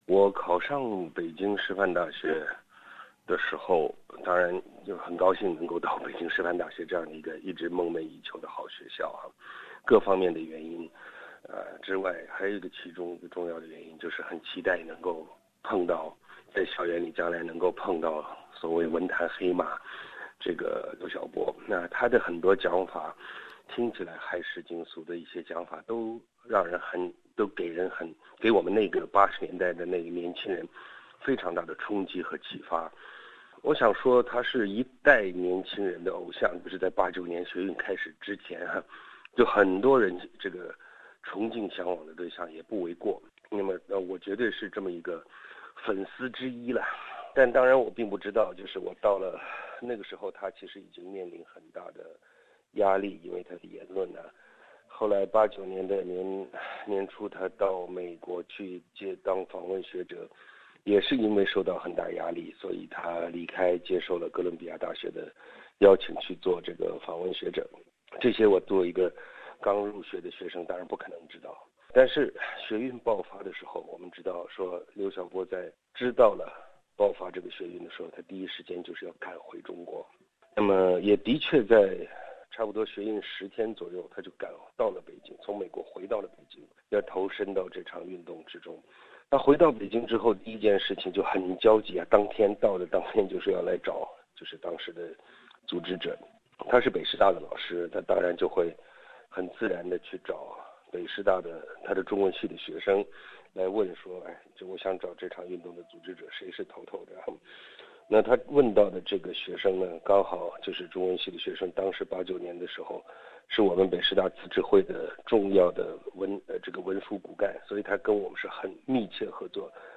在得知中国诺贝尔和平奖获得者刘晓波先生去世的消息后，前天安门学运领袖吾尔开希在脸书上直播，直播中，他热泪盈眶。此后，吾尔开希接受本节目专访，回忆起当初自己为了能见到被称为文坛黑马的刘晓波而考上北京师范大学的往事，回忆起天安门学运爆发后，刘晓波从美国回到北京，还被吾尔开希拒之门外两小时的往事，回忆起刘晓波六四后被关押两年后，与他在吾尔开希美国的家中抱头痛哭的往事，随着吾尔开希的讲述，往事历历在目。